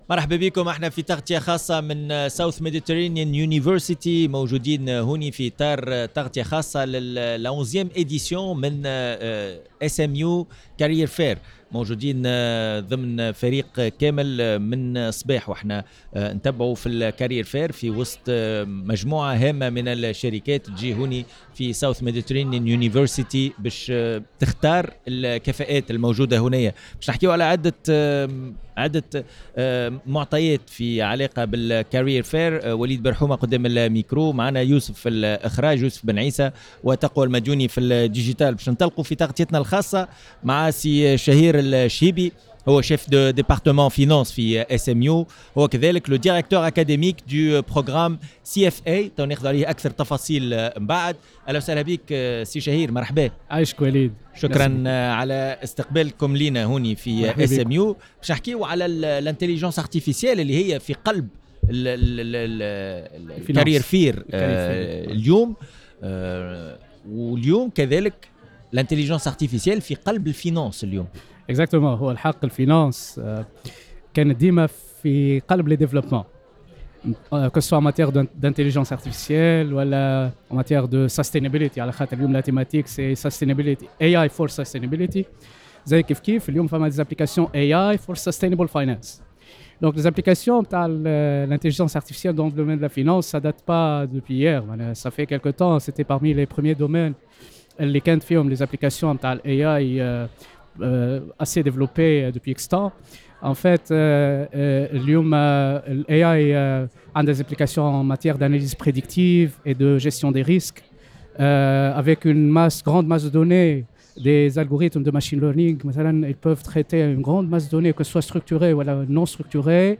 à l’occasion de la 11ème édition du « SMU Career Fair » sous le thème « AI for Sustainability »